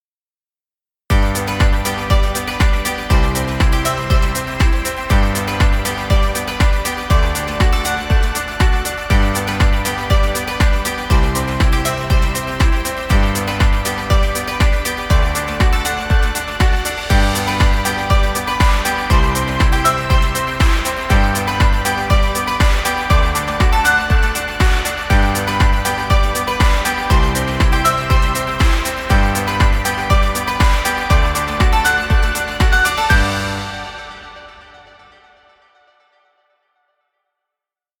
Children music.. Background music Royalty Free.
Stock Music.